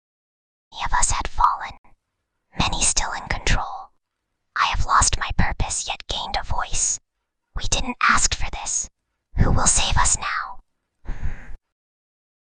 File:Whispering Girl 1.mp3
Whispering_Girl_1.mp3